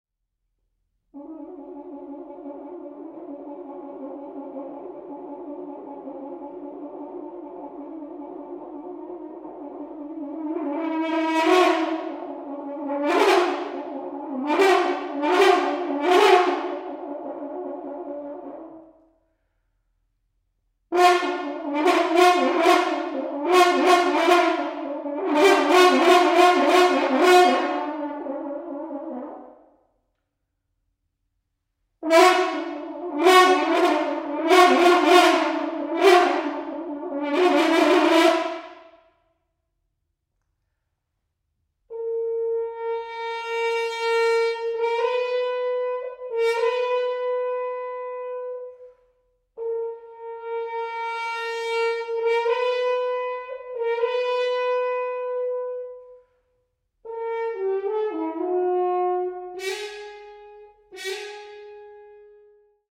UK based natural hornist